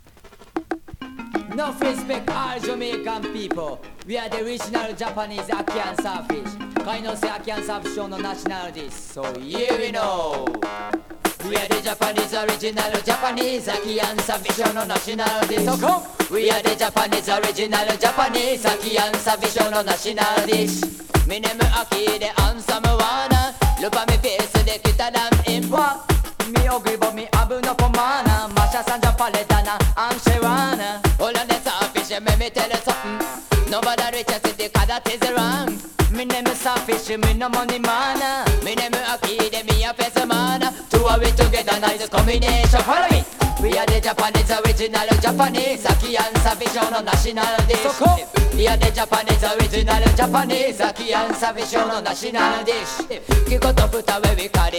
2026 NEW IN!! DANCEHALL!!
スリキズ、ノイズかなり少なめの